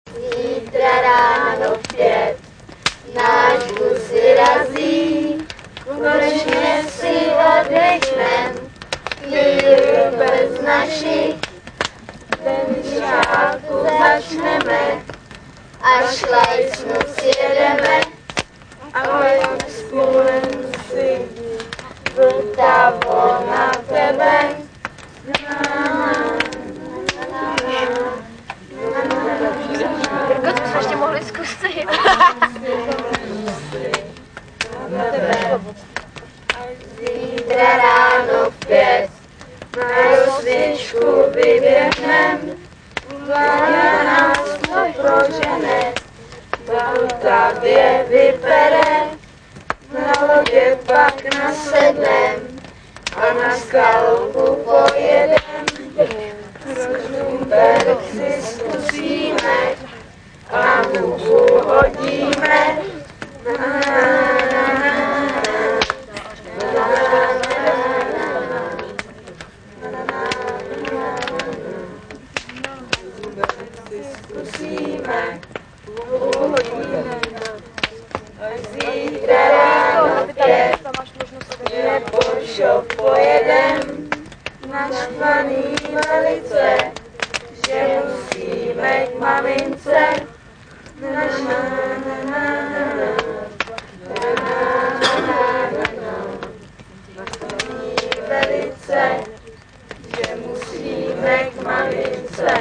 Jak už sám název praví, je to nezávislá autorsko-interpretační žabí soutěž, jejíž finále vypukne s železnou pravidelností vždy ke konci tábora.